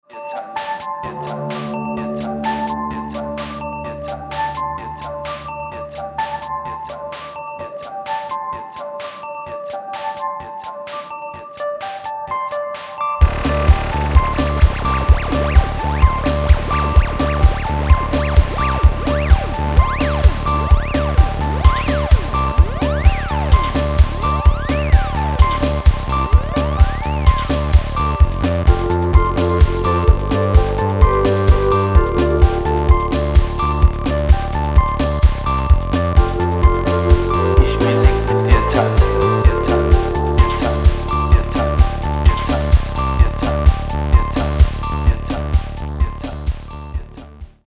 analoge Keyboards, Synthies und Effektgeräte
klassischem 60s Frauengesang